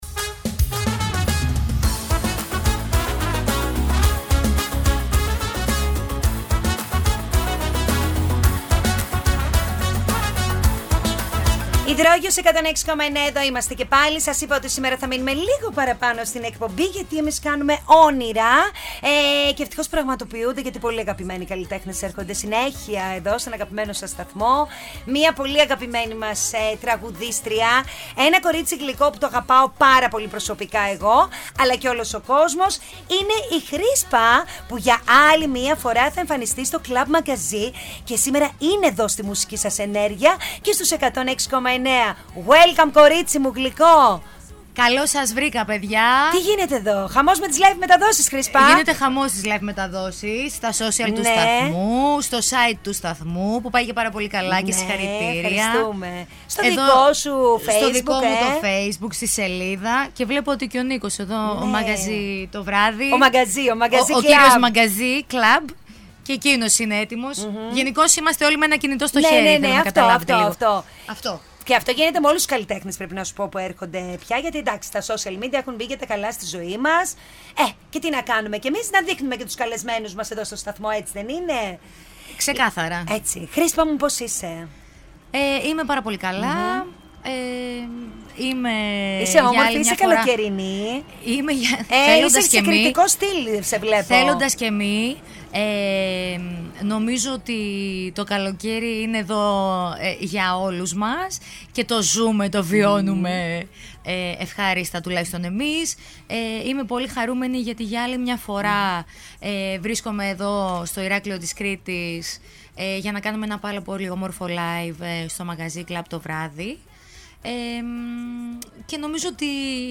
Η αγαπημένη Τραγουδίστρια ΧΡΥΣΠΑ ήρθε στον Ύδρογειο 106,9 λίγο πριν την εμφάνισή της στο club Magazi Στο Ηράκλειο.